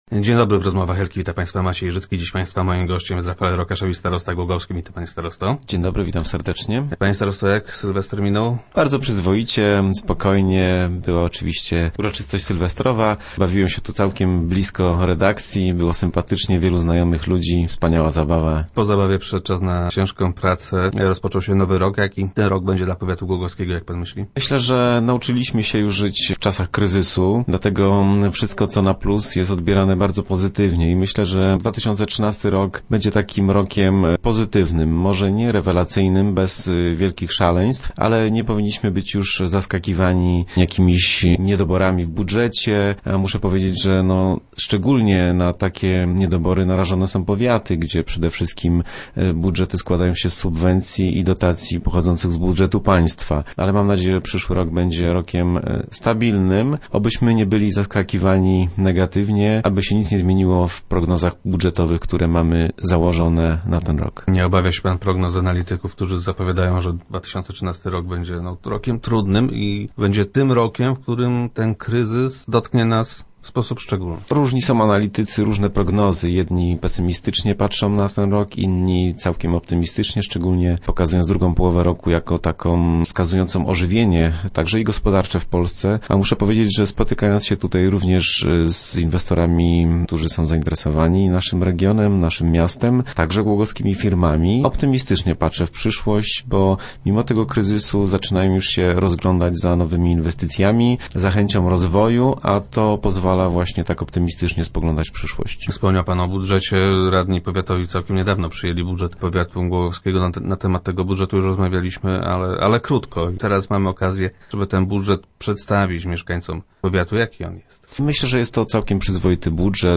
Rafael Rokaszewicz był gościem czwartkowych Rozmów Elki.